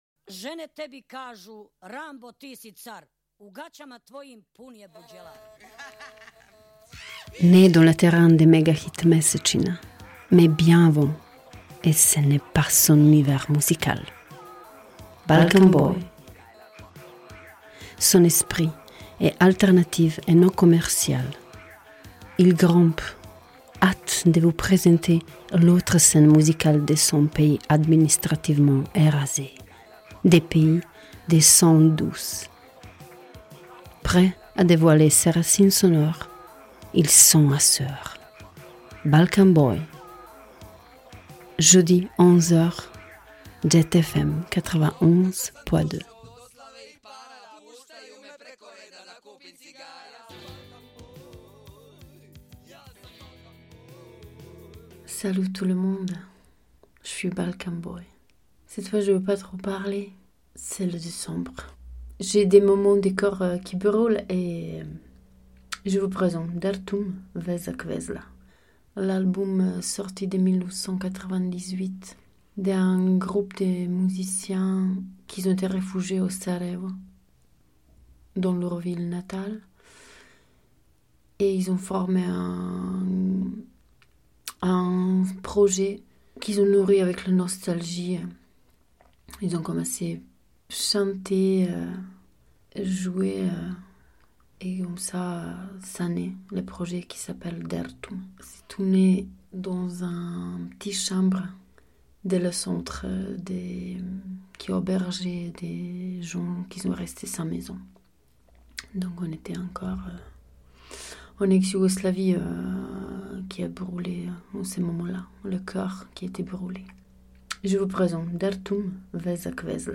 Groupe des amis , musiciens, les âmes en sevdah !